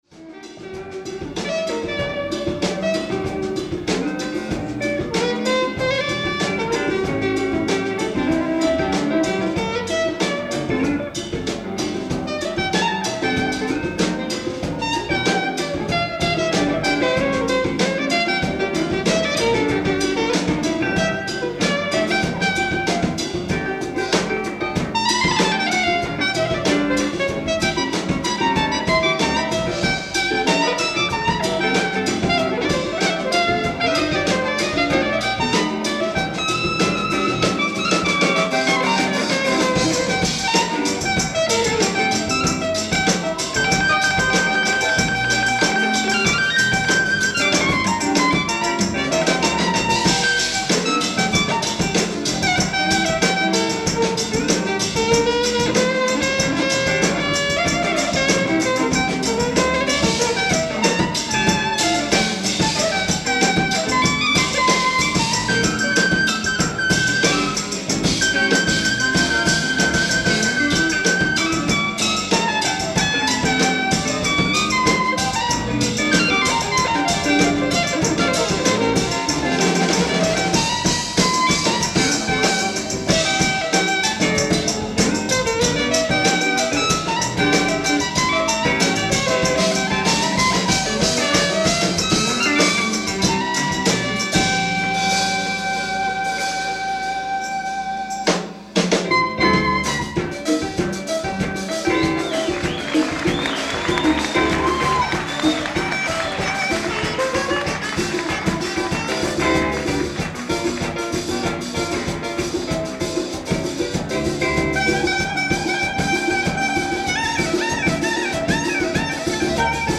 ライブ・アット・ボトムライン、ニューヨーク 01/14/1981(第１部＆第２部）
※試聴用に実際より音質を落としています。